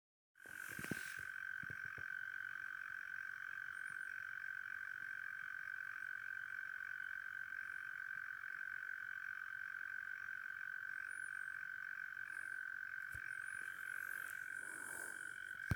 Zemesvēzis, Gryllotalpa gryllotalpa
Notes /Dzied